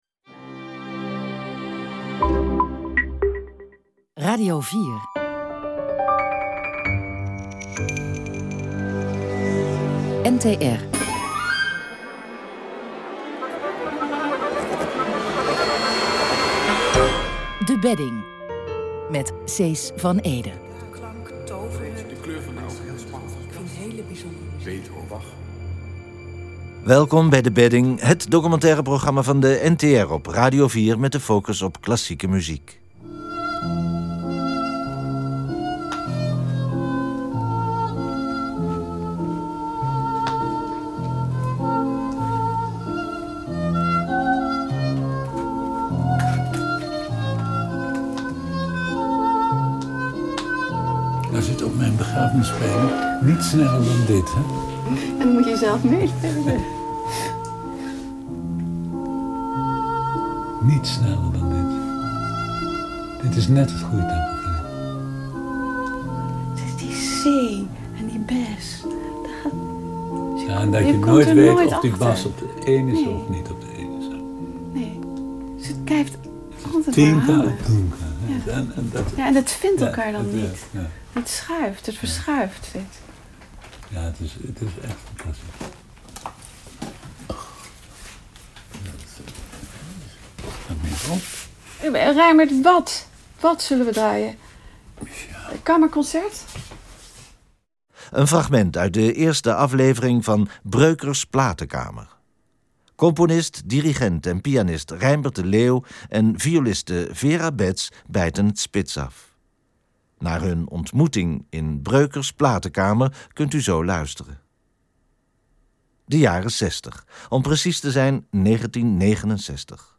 Voor de laatste drie uitzendingen van dit jaar nodigde De Bedding vrienden en geestverwanten uit om het geheim van de collectie te doorgronden. Eerste gasten zijn de pianist, dirigent en componist Reinbert de Leeuw en violiste Vera Beths.